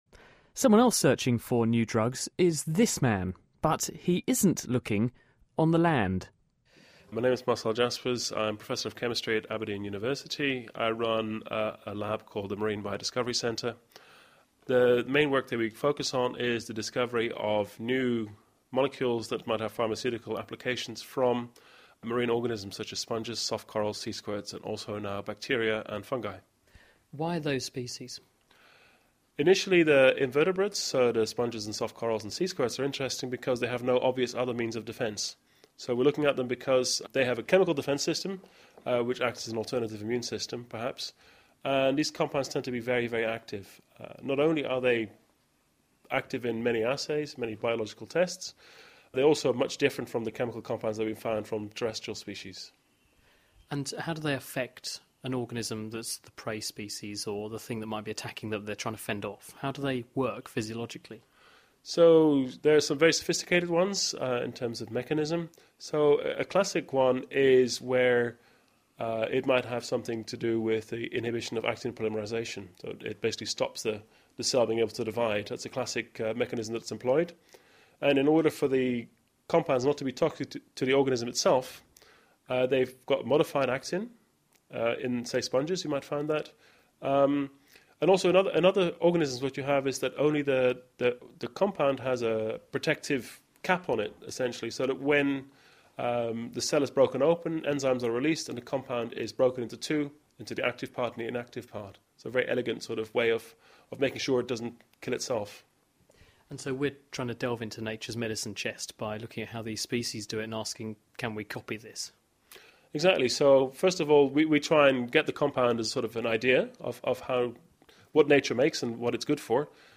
Interviews with Scientists
Interviews about medicine, science, technology and engineering with scientists and researchers internationally...